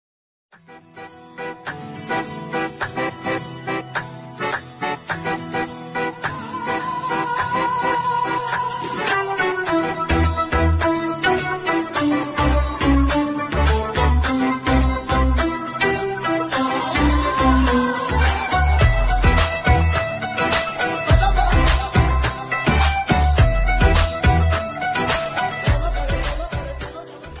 Romantic Ringtones